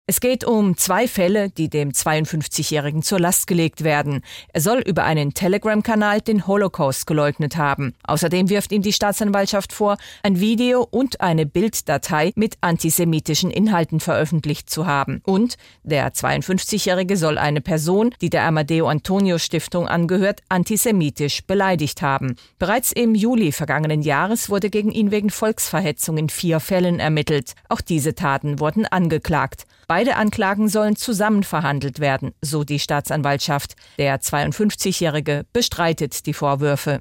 Nachrichten Staatsanwaltschaft klagt Naidoo an